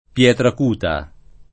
[ p LH trak 2 ta ]